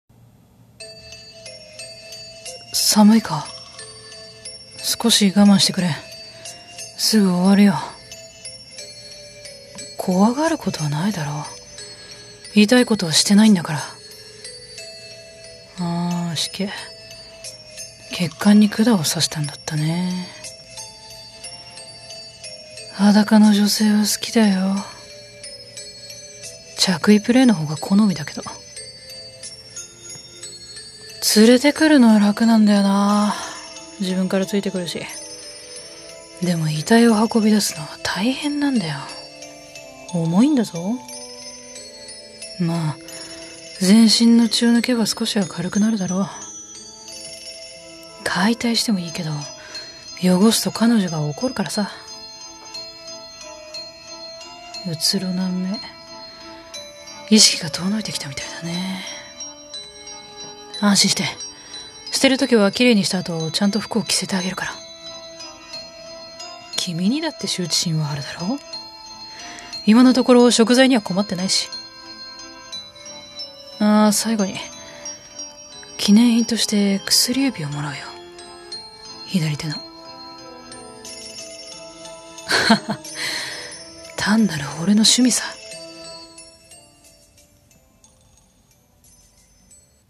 【声劇】記念品